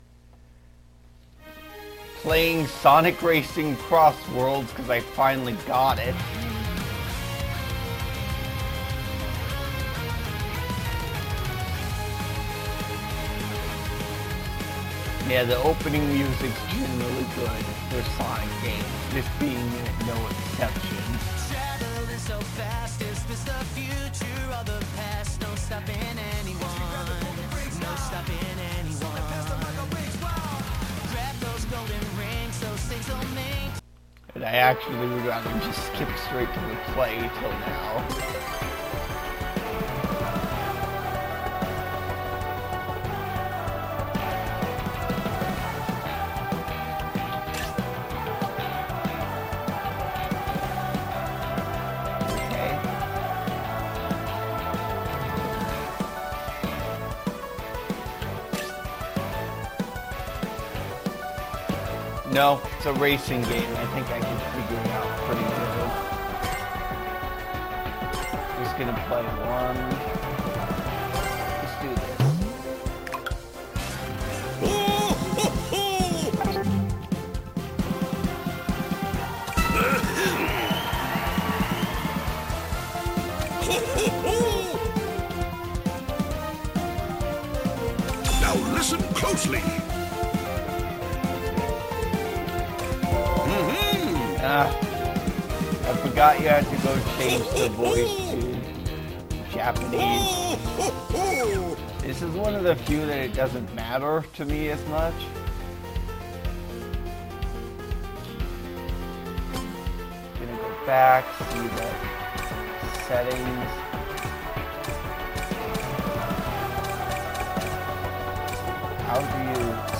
I play Sonic Racing Crossworlds with commentary